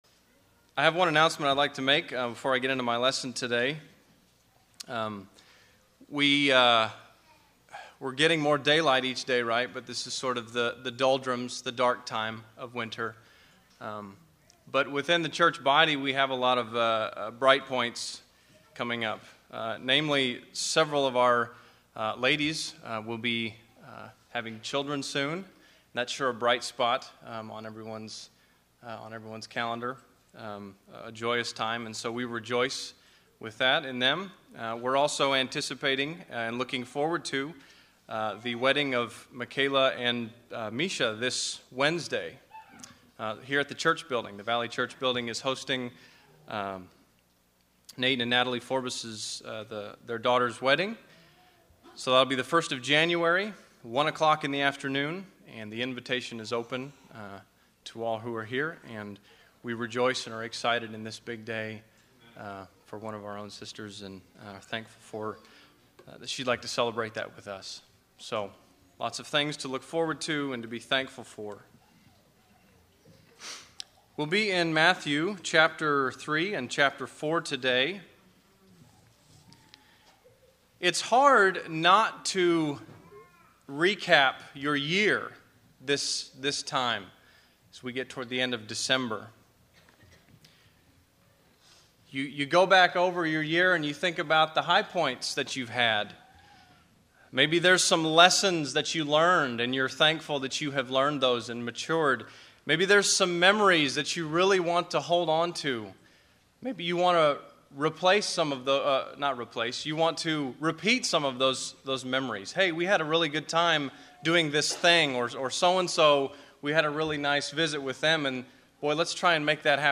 Valley church of Christ - Matanuska-Susitna Valley Alaska
Audio Sermons